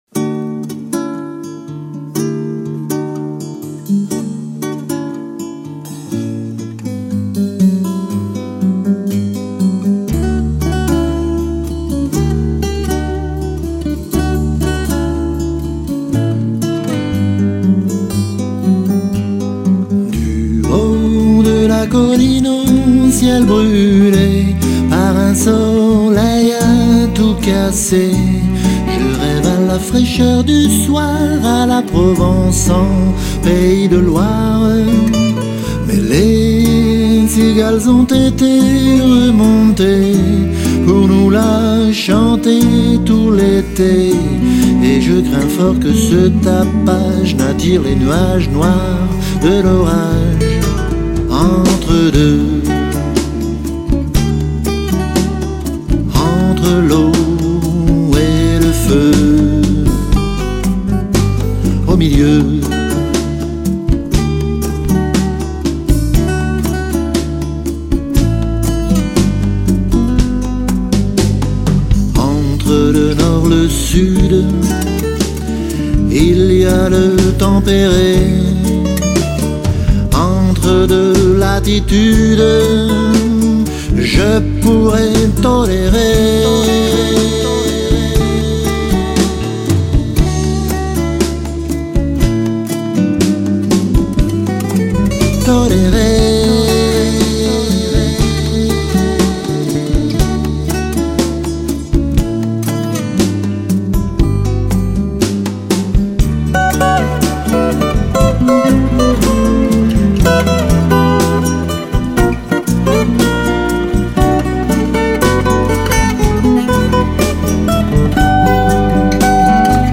une ambiance plus mélancolique, plus blues, plus bossa